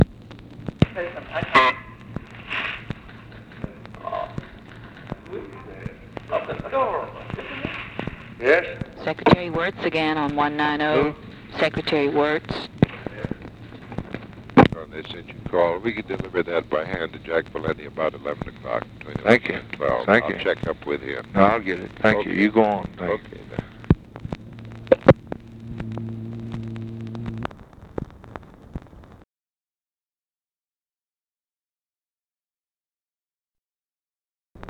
Conversation with WILLARD WIRTZ, April 27, 1964
Secret White House Tapes